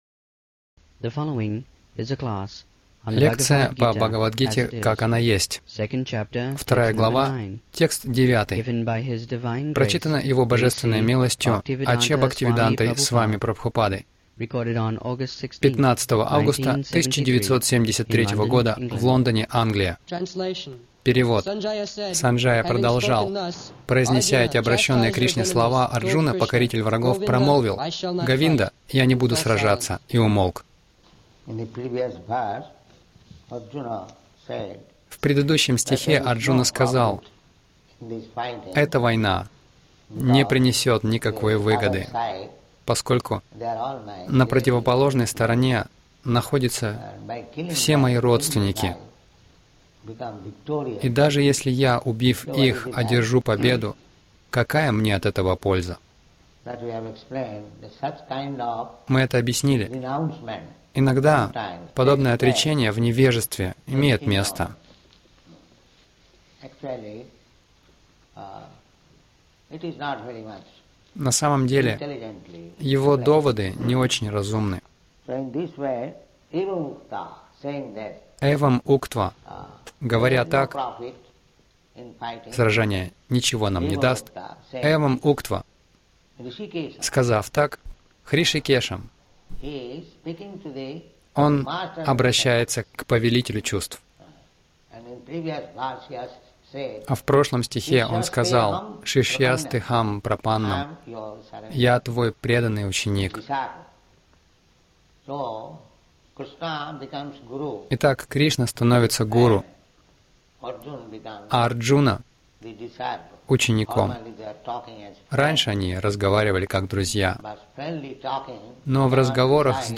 Милость Прабхупады Аудиолекции и книги 16.08.1973 Бхагавад Гита | Лондон БГ 02.09 — Как и чем удовлетворить чувства Загрузка...